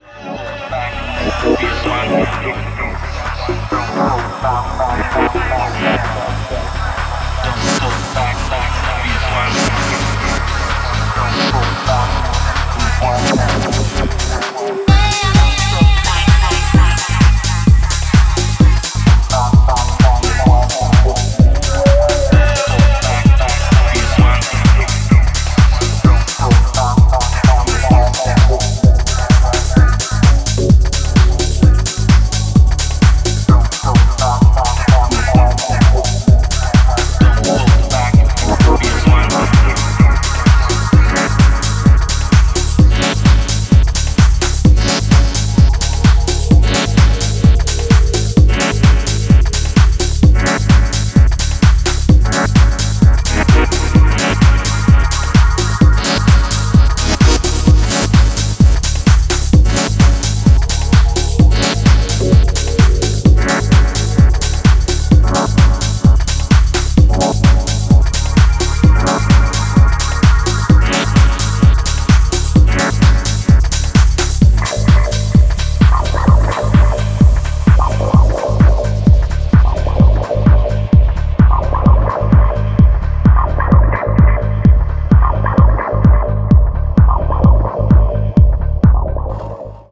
ダビーな処理を施されて空間に溶け込むヴォイスチョップとシンセがサイケデリックな
UKGからの影響も感じられるベースラインの圧の強さが新質感のモダン・ディープ・ハウスを展開